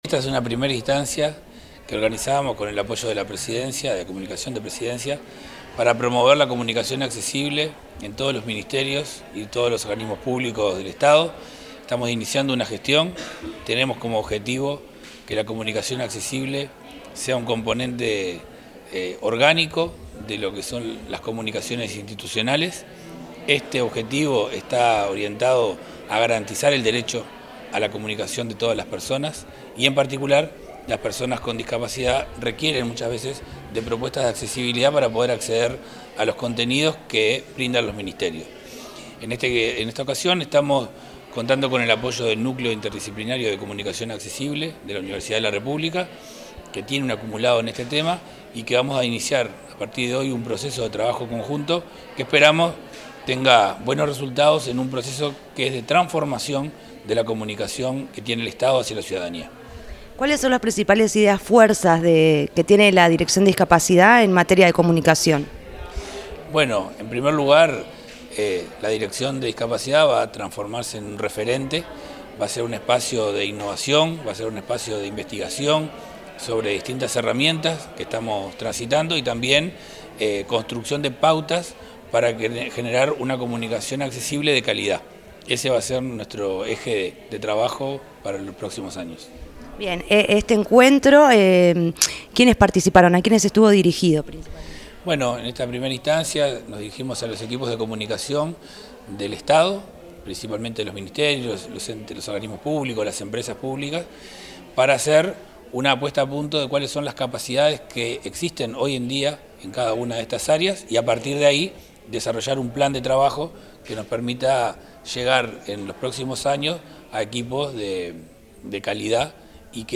Entrevista al director del Área de Discapacidad del Ministerio de Desarrollo Social, Federico Lezama